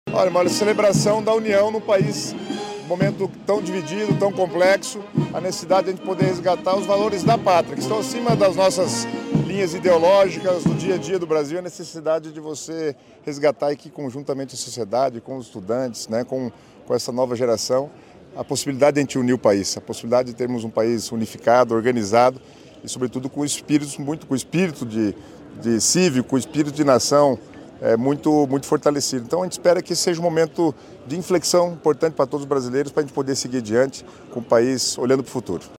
Sonora do secretário Estadual das Cidades, Guto Silva, sobre o desfile de 7 de setembro